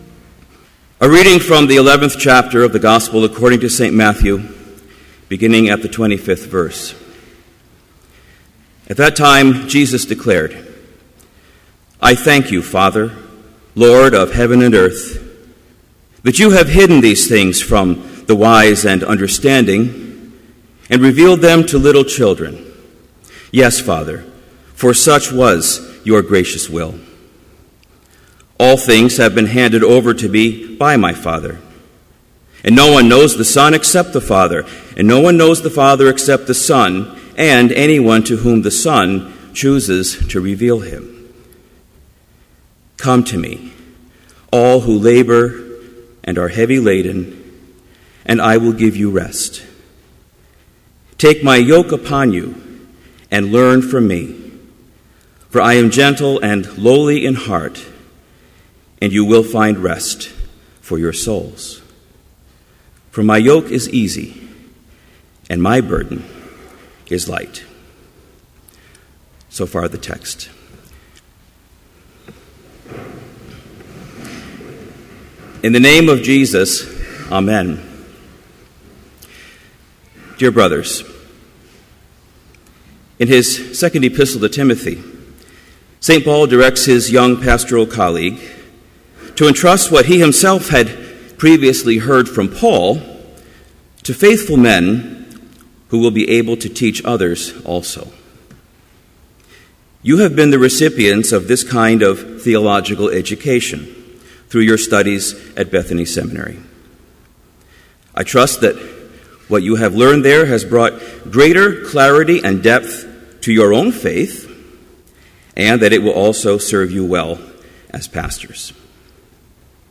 Complete Service
• BETHANY LUTHERAN THEOLOGICAL SEMINARY GRADUATION
• Prelude
• Postlude
This Chapel Service was held in Trinity Chapel at Bethany Lutheran College on Tuesday, December 3, 2013, at 10 a.m. Page and hymn numbers are from the Evangelical Lutheran Hymnary.